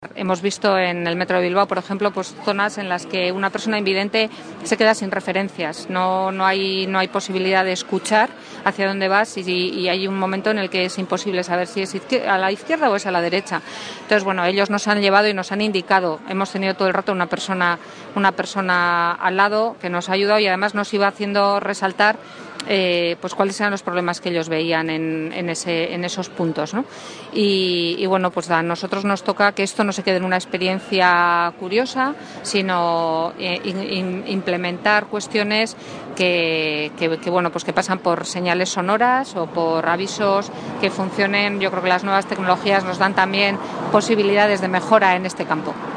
La consejera de Medio Ambiente y Política Territorial del Gobierno Vasco, Ana Oregi, aseguró que “el Gobierno Vasco actualiza y mejora permanentemente la accesibilidad de las personas a las infraestructuras de transporte, como estos días acoplando las paradas y andenes del tranvía de Vitoria-Gasteiz a las nuevas medidas de las sillas de ruedas motorizadas”, entre otras iniciativas.